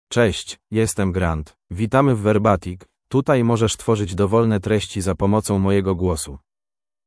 GrantMale Polish AI voice
Grant is a male AI voice for Polish (Poland).
Voice sample
Listen to Grant's male Polish voice.
Grant delivers clear pronunciation with authentic Poland Polish intonation, making your content sound professionally produced.